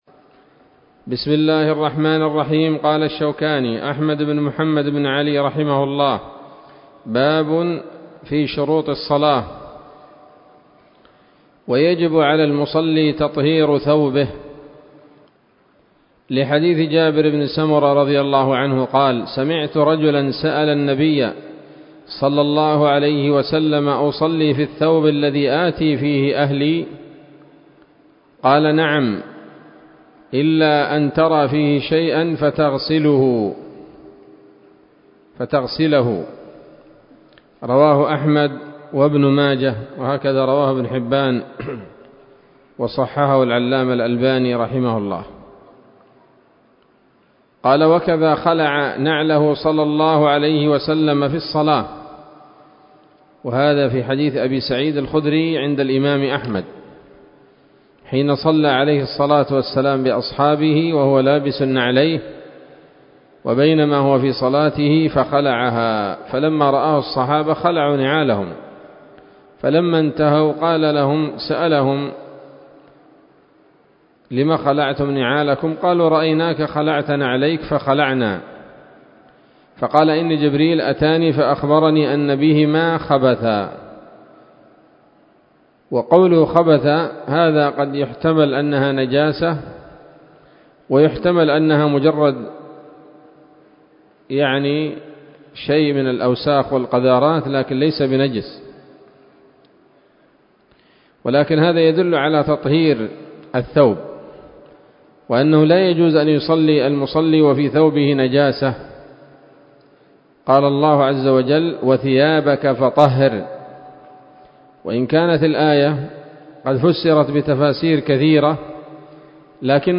الدرس الثامن من كتاب الصلاة من السموط الذهبية الحاوية للدرر البهية